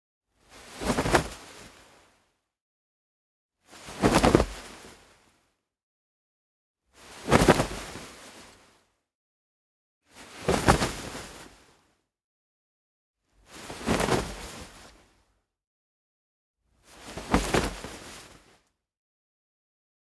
household
Cloth Movement Throw 7